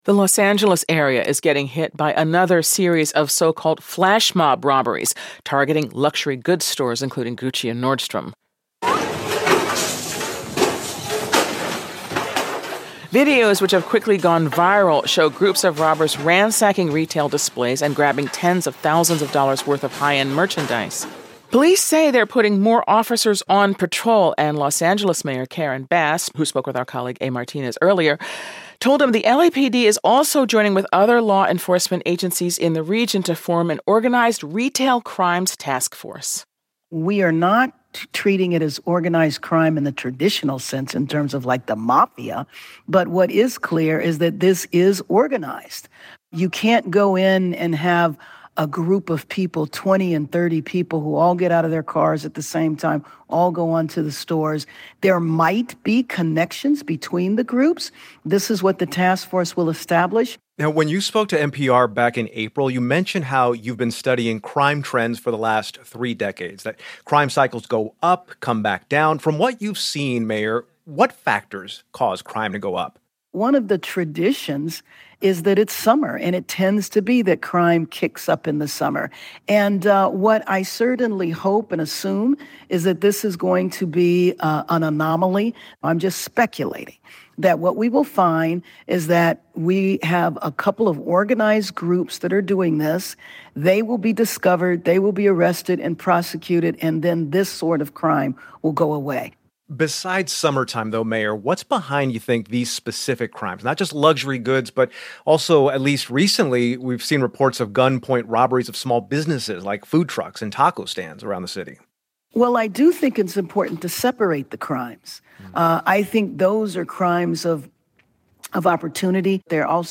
NPR's A Martinez talks with Los Angeles Mayor Karen Bass about a rash of smash-and-grab robberies in Southern California.